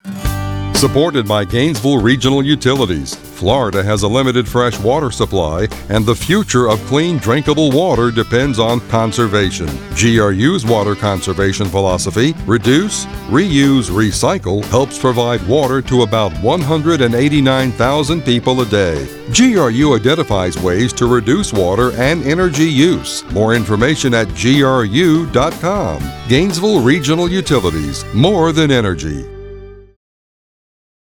Length Radio Spot   Length Radio Spot